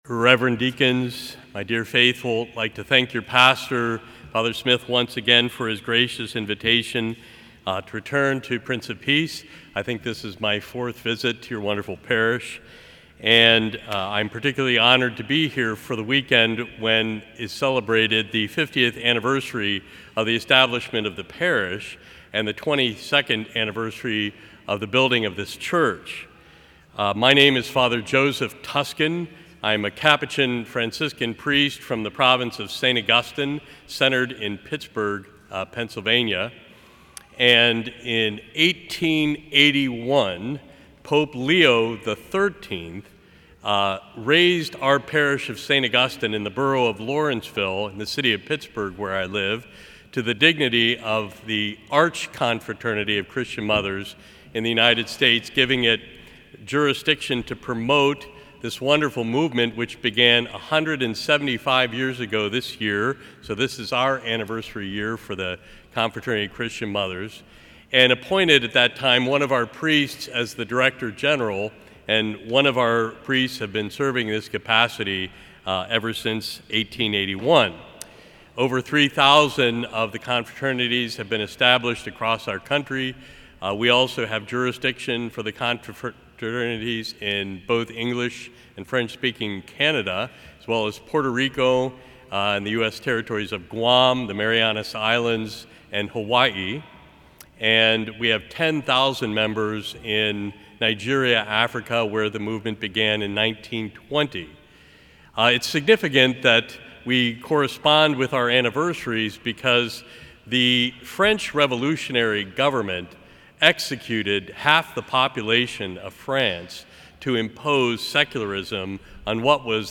More Homilies